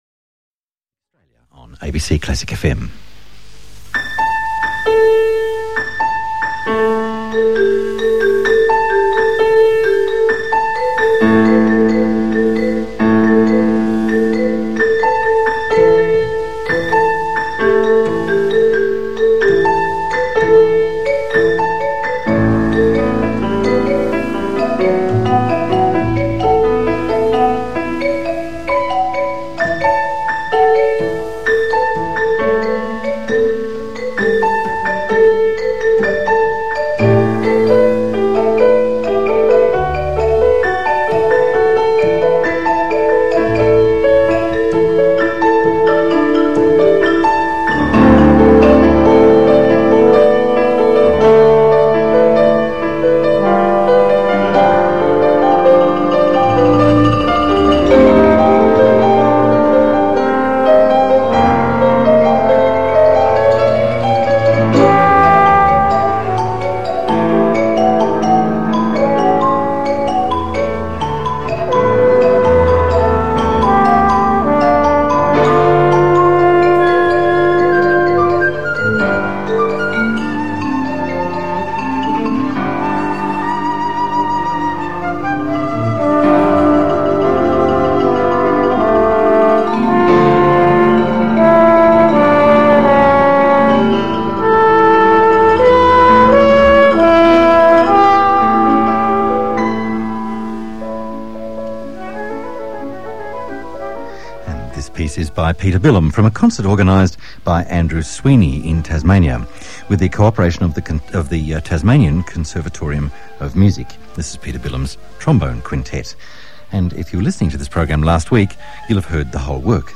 interviews
Apart from this transcription into html, there is also an old cassette recording of parts of the original broadcast, recently rescued into mp3 form.
interview.mp3